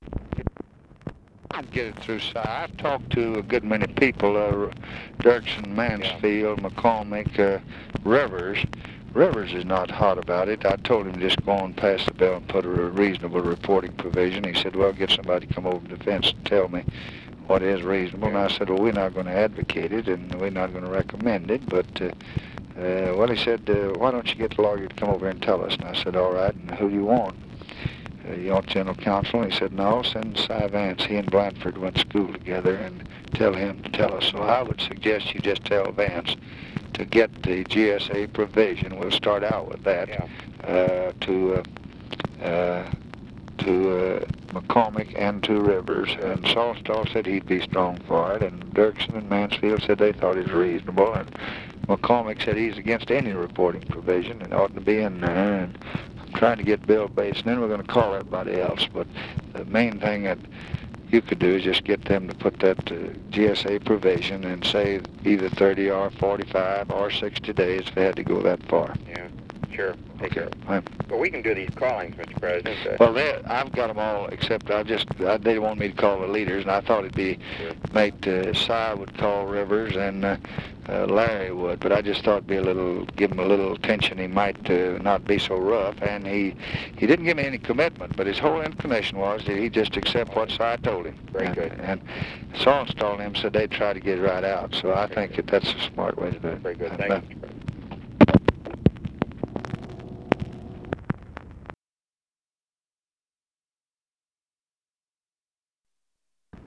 Conversation with ROBERT MCNAMARA, August 21, 1965
Secret White House Tapes